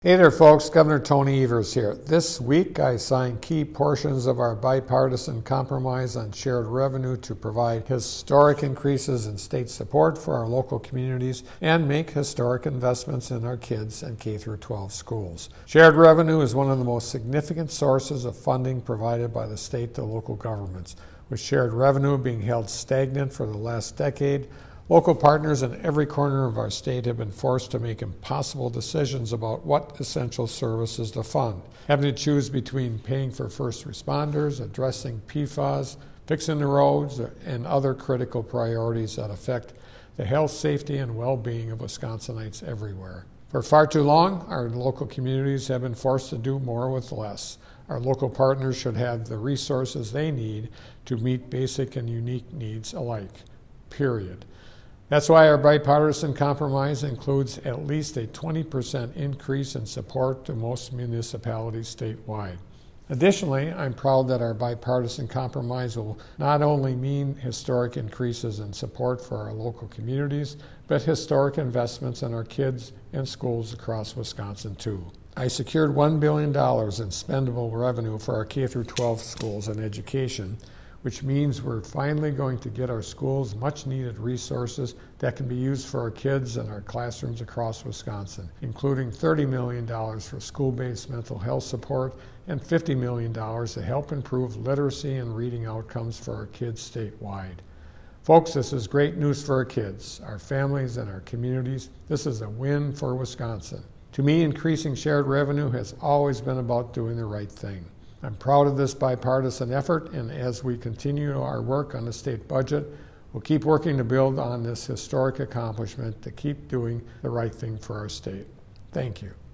Weekly Dem radio address: Gov. Evers on Historic Shared Revenue Increases for Local Communities - WisPolitics
MADISON — Gov. Tony Evers today delivered the Democratic Radio Address on the legislation signed earlier this week providing historic shared revenue increases for local communities, including a 20 percent increase in support to most municipalities statewide.